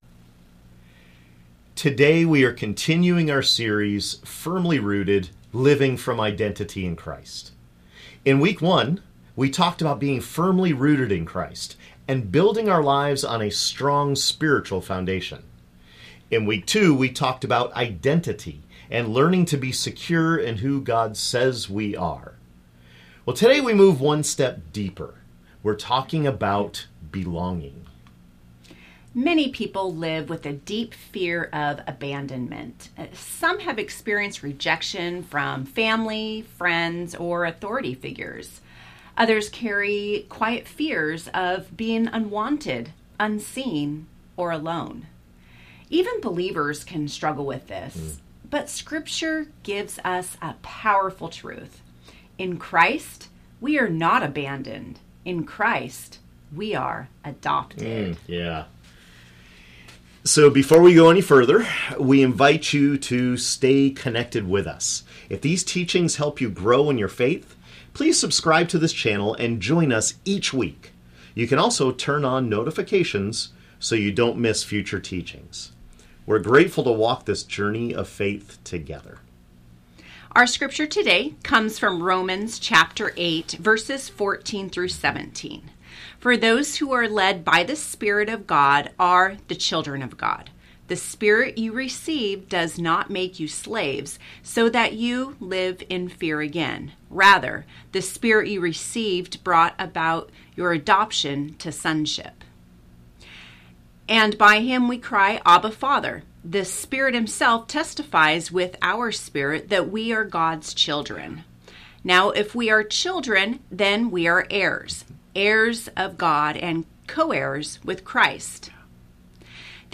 This teaching explores what it means to live as people who are adopted into God’s family and no longer abandoned or alone. When we understand our belonging in Christ, fear loses its power and confidence, hope, and trust begin to grow.